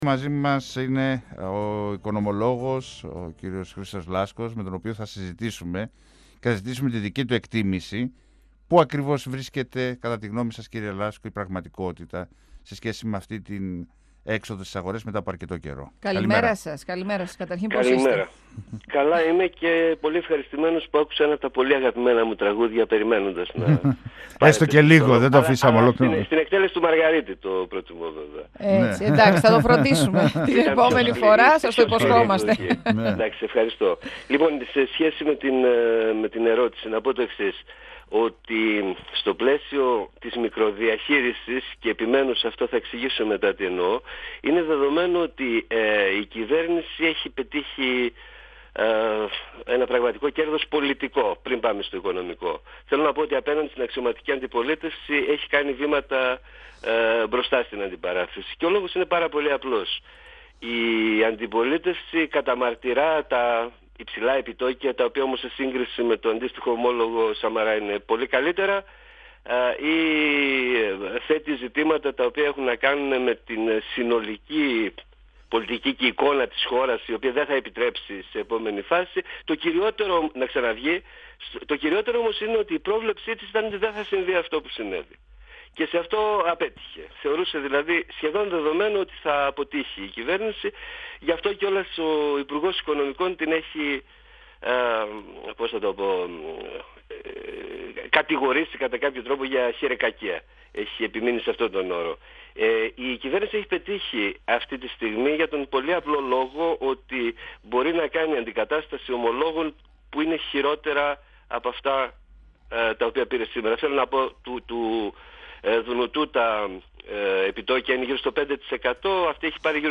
Η επιτυχία των ελληνικών ομολόγων ήταν αναμενόμενη λόγω της υψηλής απόδοσης, ωστόσο το χρέος παραμένει πολύ υψηλό. 102FM Συνεντεύξεις ΕΡΤ3